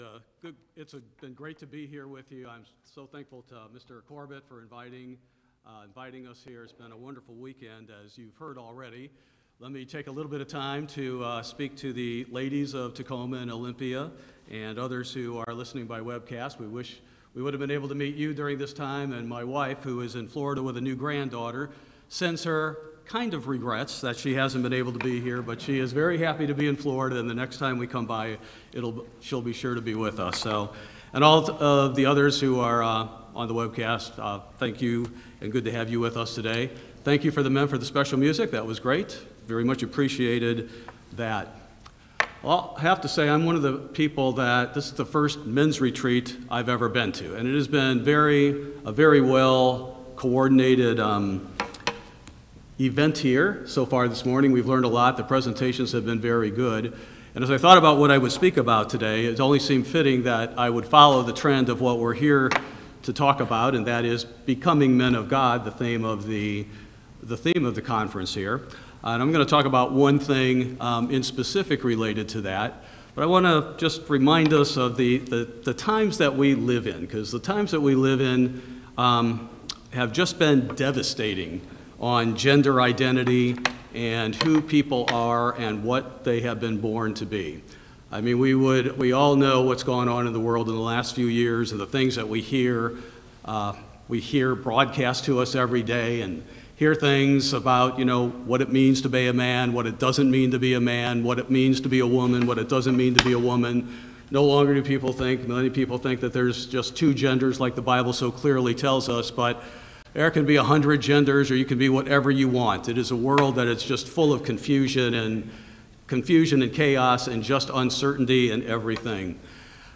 This sermon, given at the Tacoma, Washington, men's weekend, considers what we can learn about masculinity as God defines it, and as it will be taught in His Kingdom.